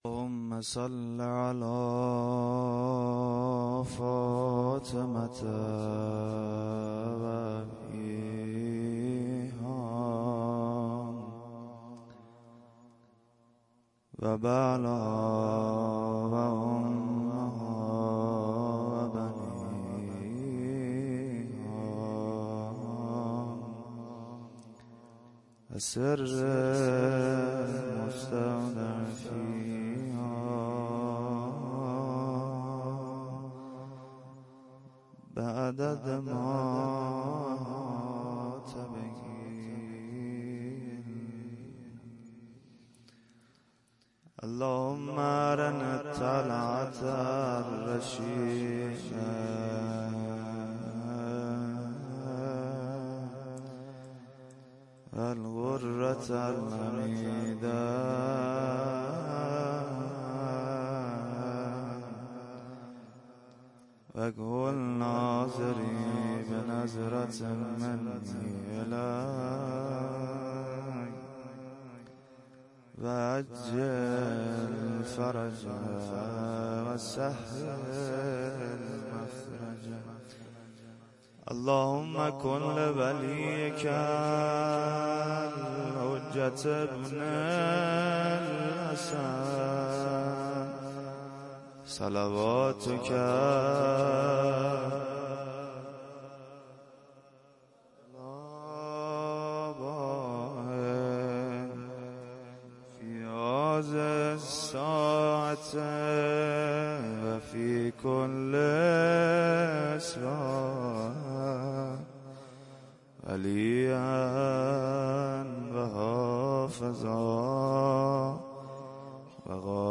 سخنرانی(موضوع جوان_فاطمی_۱ )
شهادت امام جعفرصادق(ع) ـ ۱۴۳۹ ـ جمعه ۱۵ تیر ۱۳۹۷